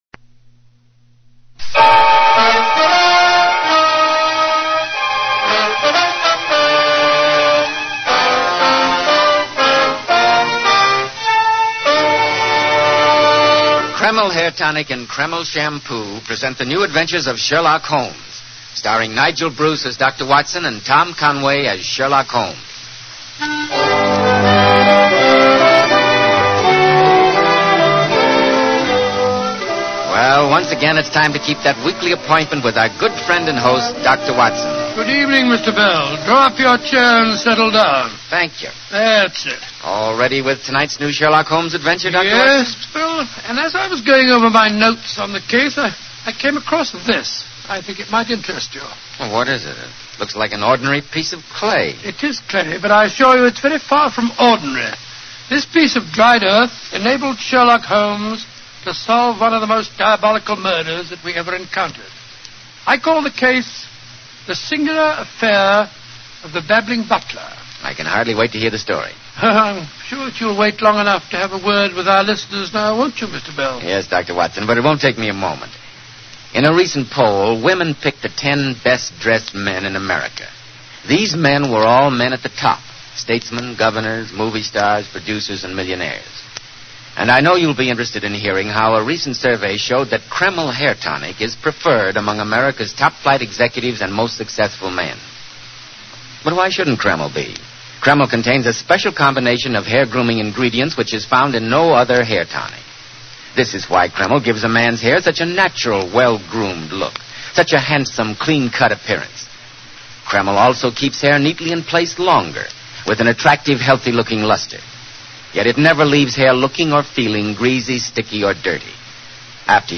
Radio Show Drama with Sherlock Holmes - The Babbling Butler 1947